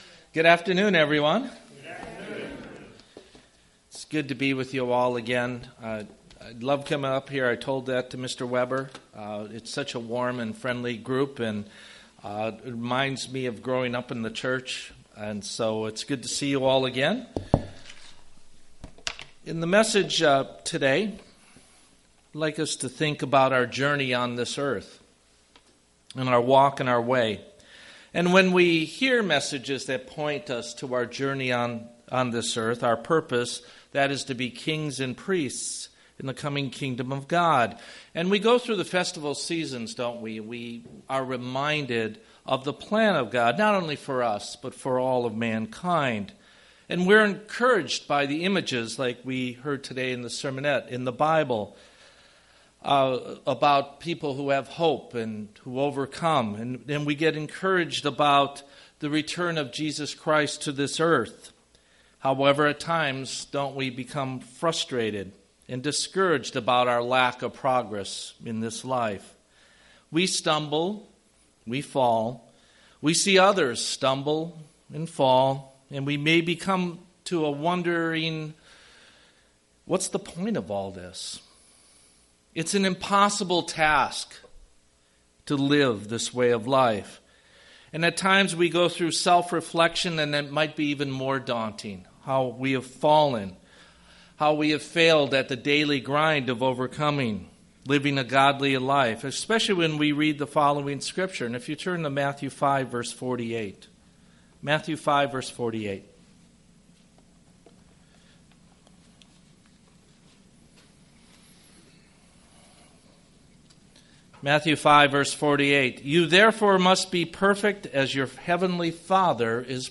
Sermon
Given in Redlands, CA